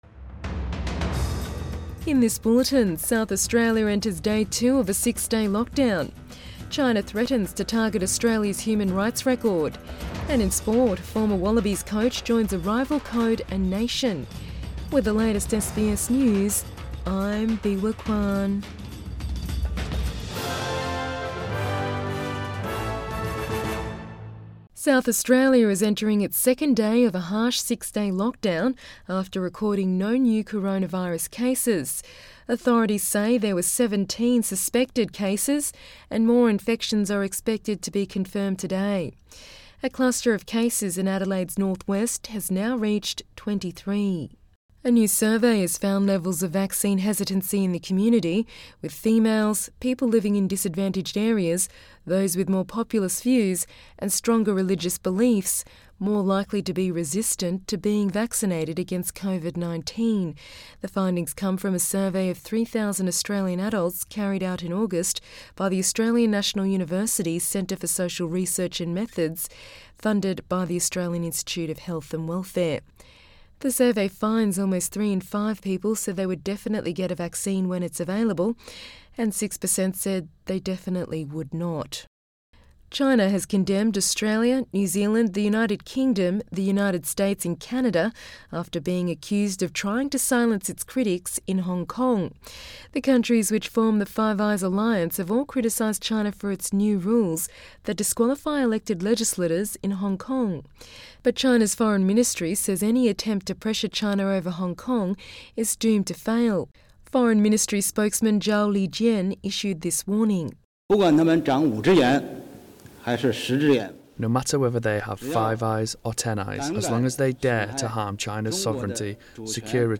AM bulletin 20 November 2020